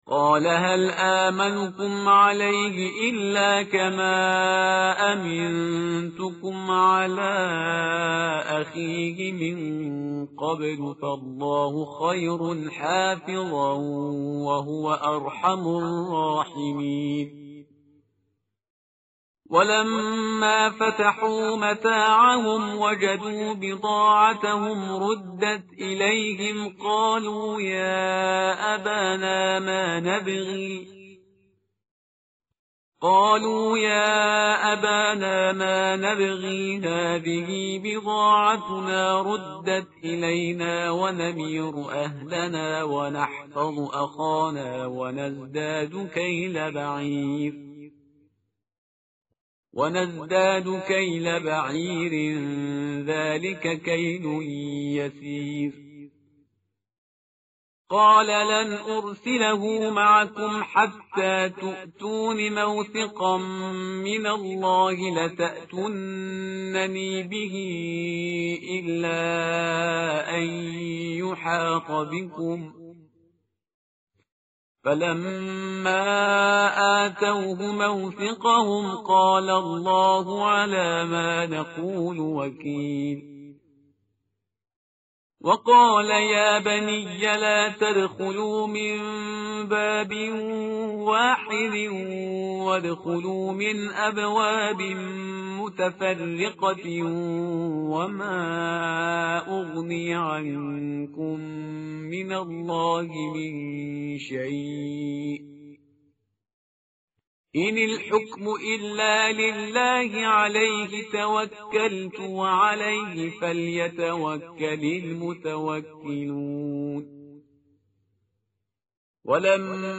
متن قرآن همراه باتلاوت قرآن و ترجمه
tartil_parhizgar_page_243.mp3